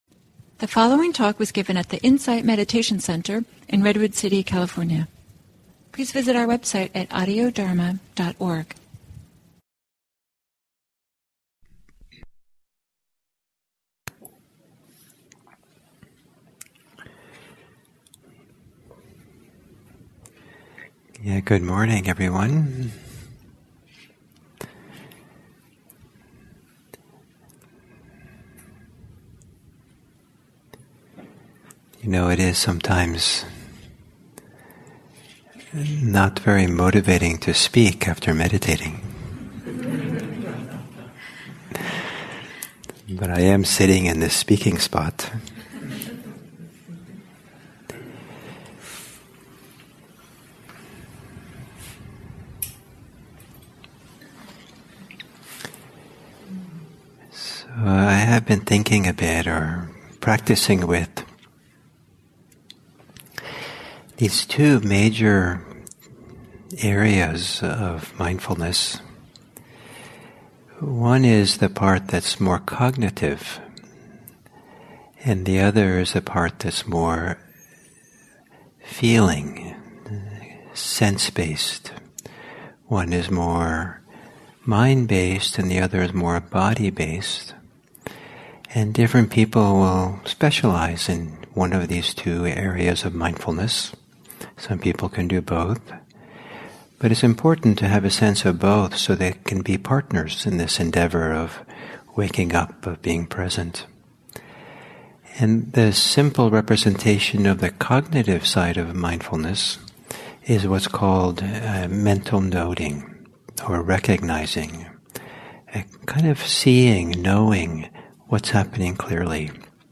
Talks
at the Insight Meditation Center in Redwood City, CA